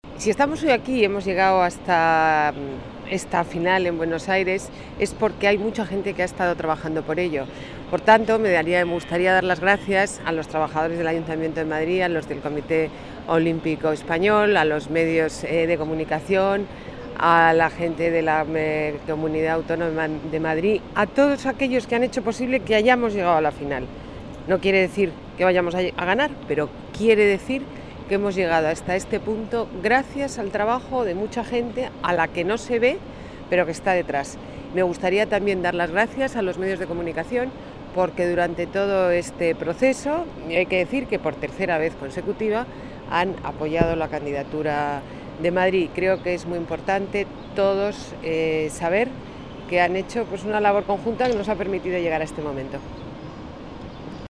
Nueva ventana:Declaraciones de Ana Botella: gracias al equipo de Madrid 2020